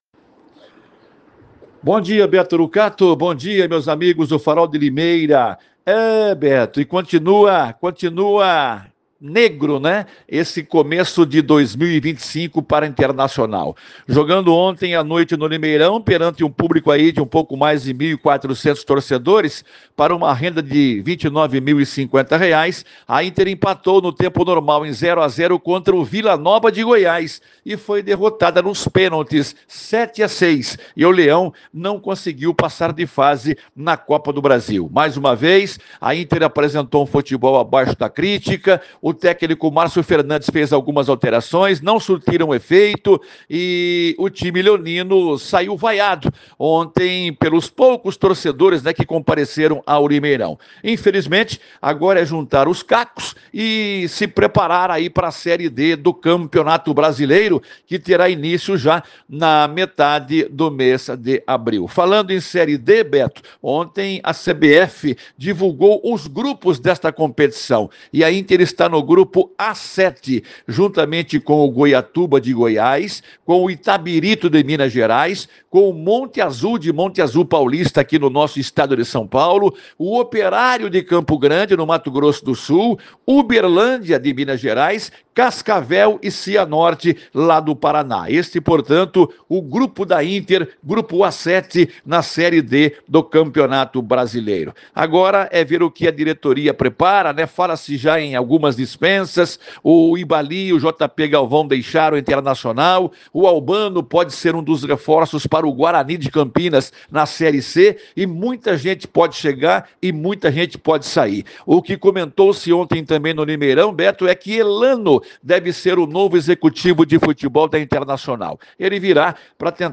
ESPORTES